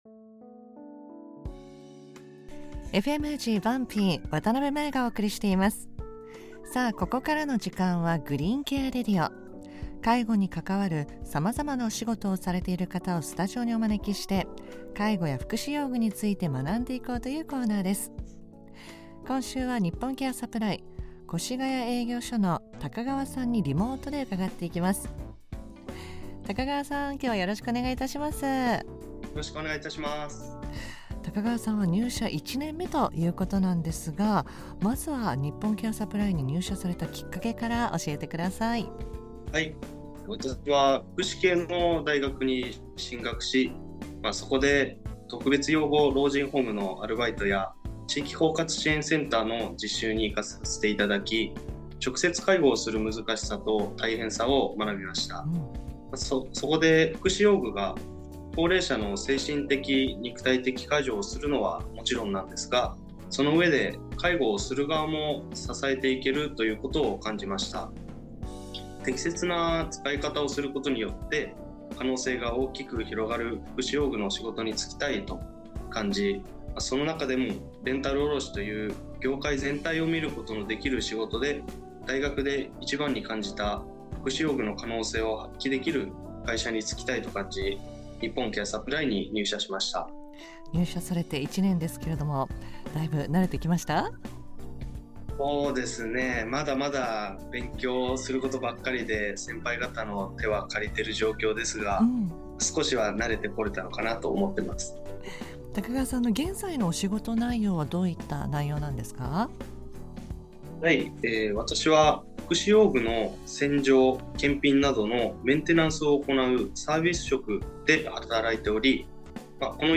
リモートで お話を伺いました。